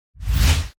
通過音（WHOOSH）さっ
びゅーん
se_whoosh.mp3